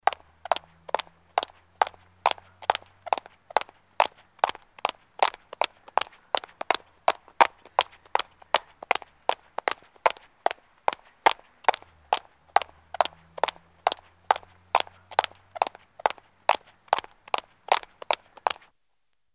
急促的高跟鞋3.mp3
通用动作/01人物/01移动状态/高跟鞋/急促的高跟鞋3.mp3
• 声道 立體聲 (2ch)